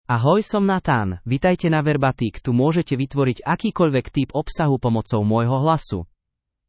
NathanMale Slovak AI voice
Nathan is a male AI voice for Slovak (Slovakia).
Voice sample
Listen to Nathan's male Slovak voice.
Male
Nathan delivers clear pronunciation with authentic Slovakia Slovak intonation, making your content sound professionally produced.